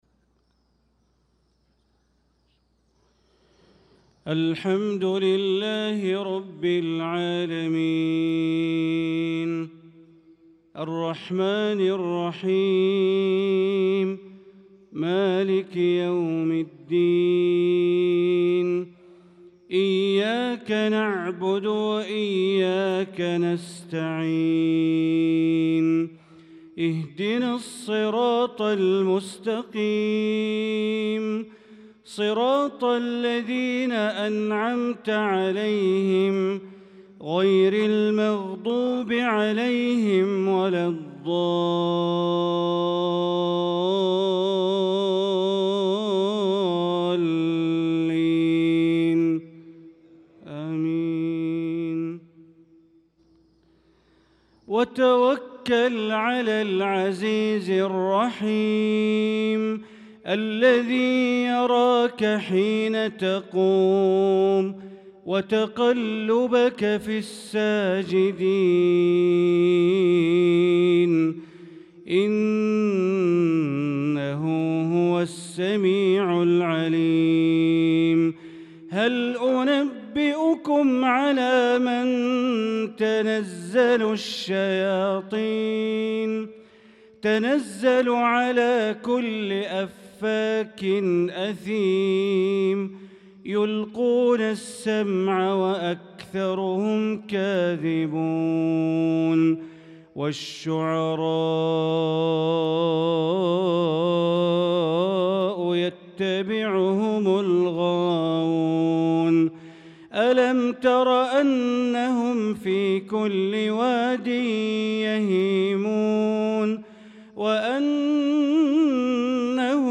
صلاة المغرب للقارئ بندر بليلة 29 شوال 1445 هـ